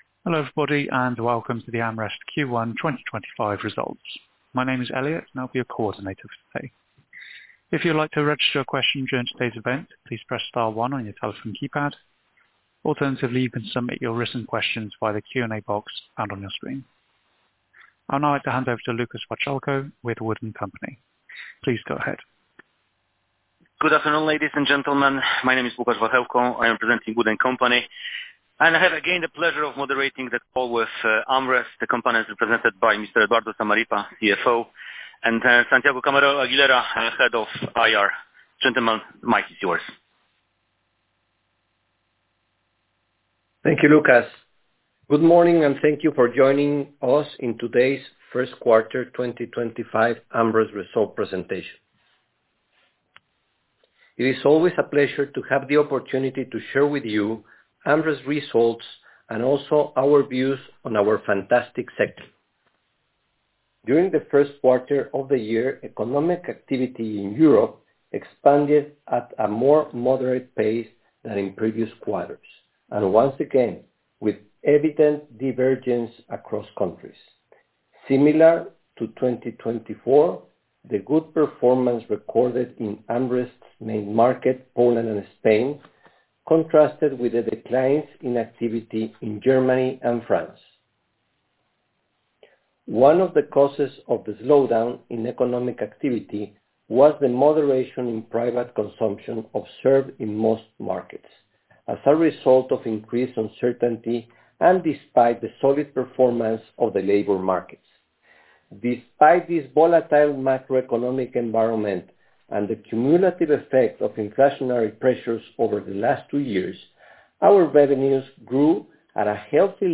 Teleconferencia con inversores